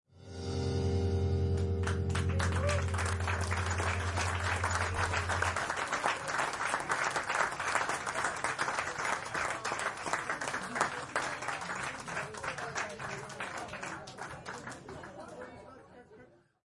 描述：在前爵士乐演唱会Tunel Rijeka的音乐会期间掌声。
Tag: 手拍手 aplause 观众 气氛 一pplaud 掌声